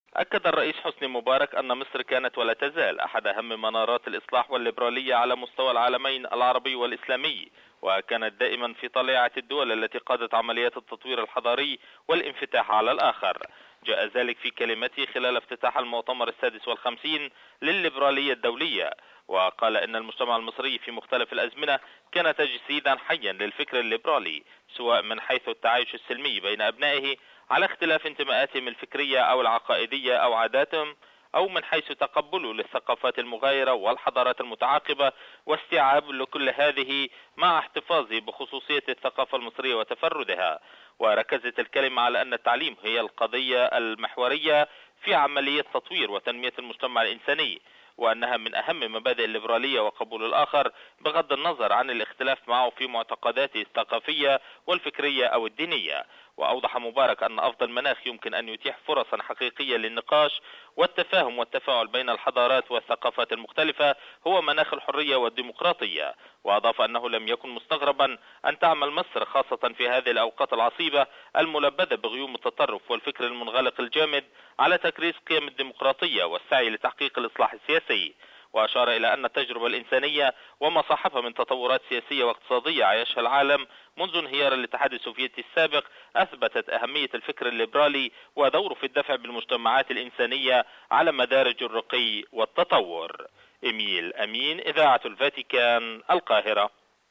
التفاصيل في تقرير مراسلنا في العاصمة المصرية.